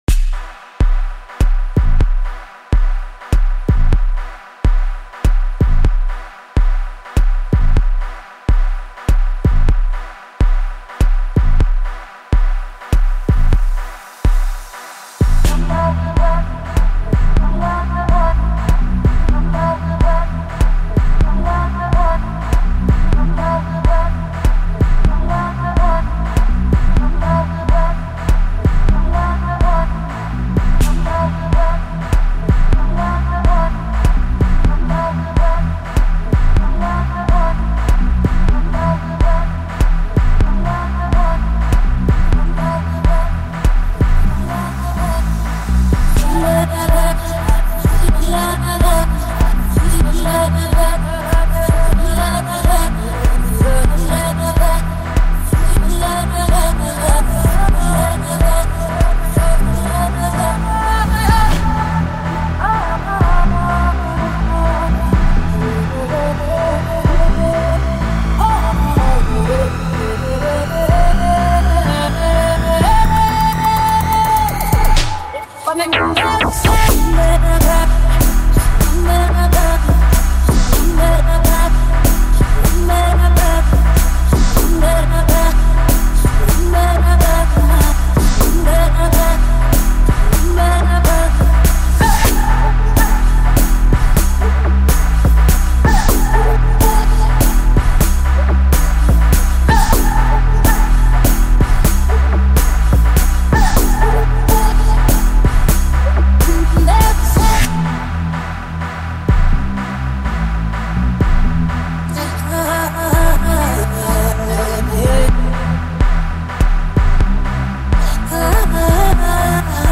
catchy hooks, smooth flow